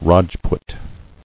[Raj·put]